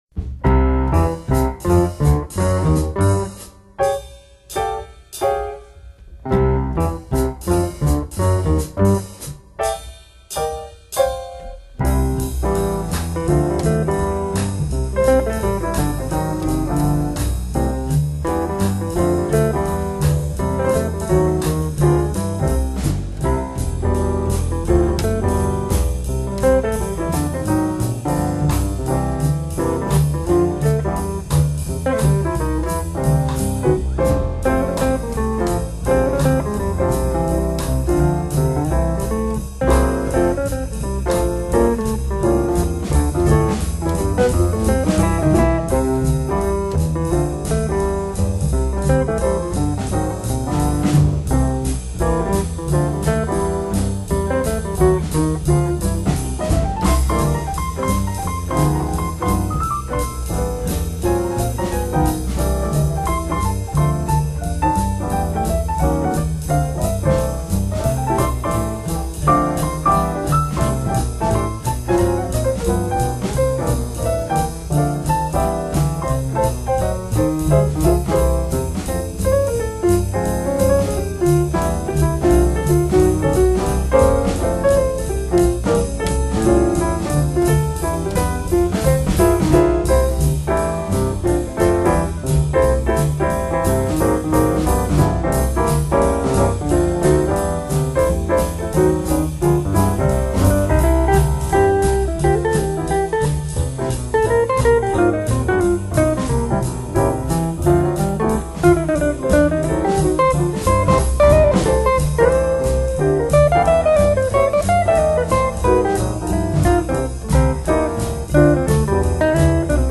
Styles: Jazz, Piano Jazz, Jazz Instrumental